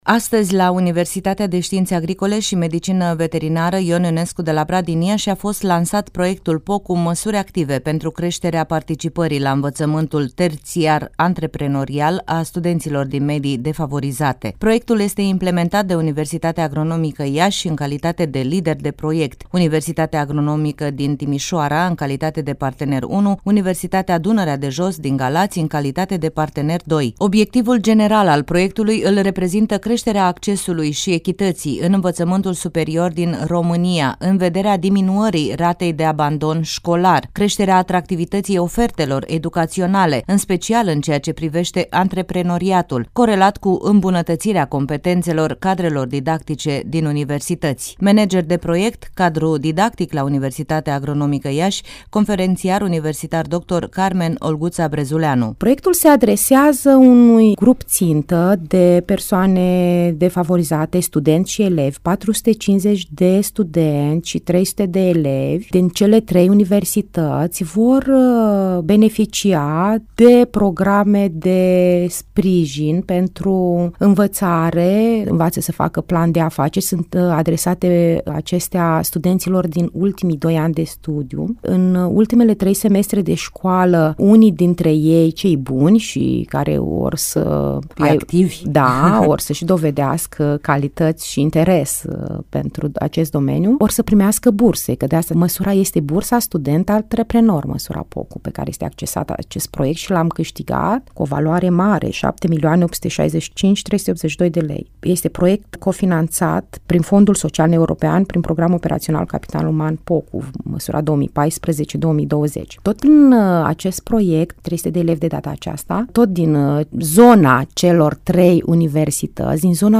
ne-a vorbit despre noul program la Radio România Iaşi: